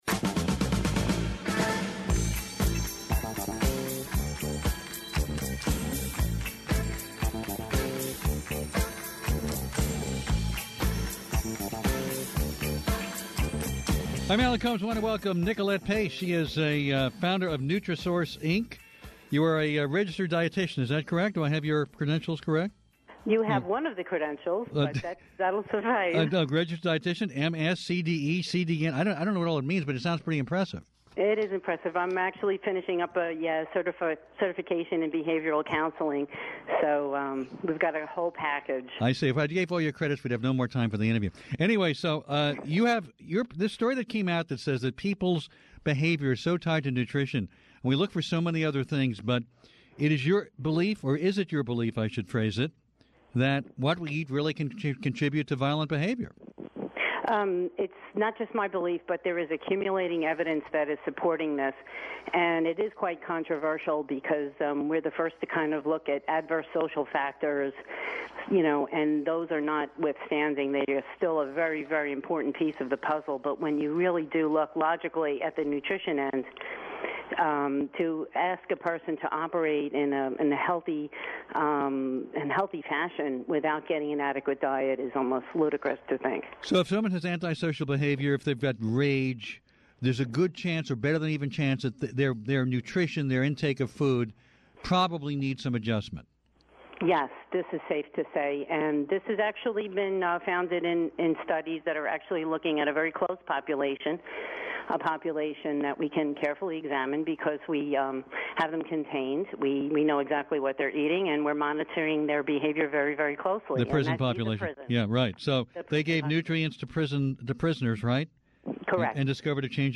Listen in as Fox News Radio personality Alan Colmes gets advice